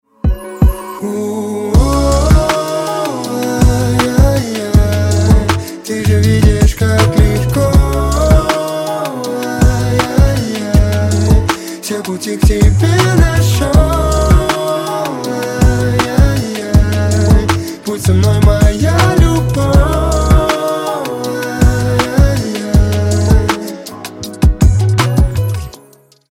Спокойные И Тихие Рингтоны
Поп Рингтоны Новинки